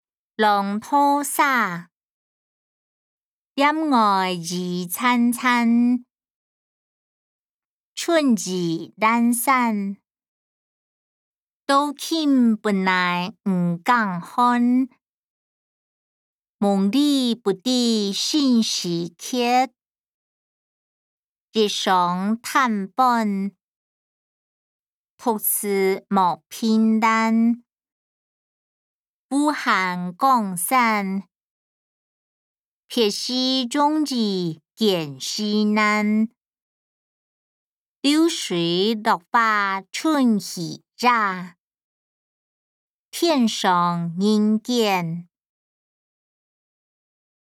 詞、曲-浪淘沙音檔(海陸腔)